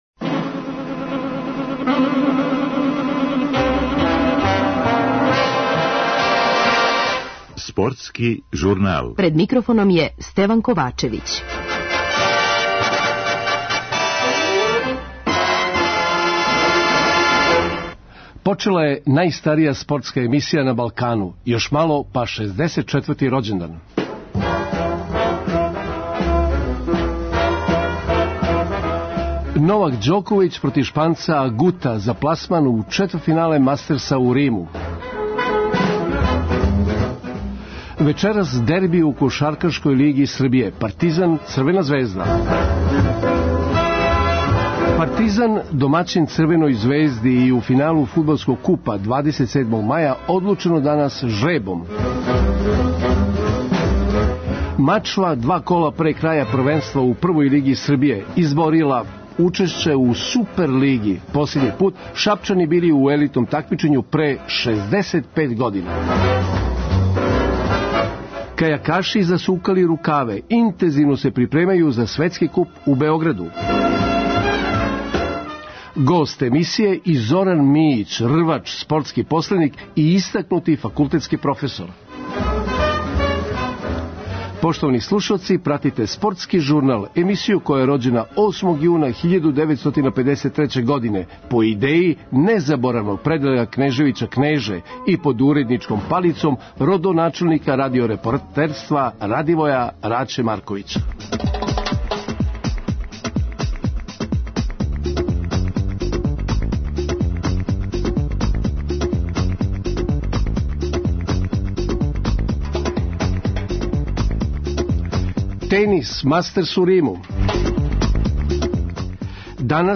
Уз Куп, црно-бели су и на корак до титуле шампиона после јучерашњом победом над Радничким у Нишу – 3:1 – истиче наш репортер у репортажи са Чаира.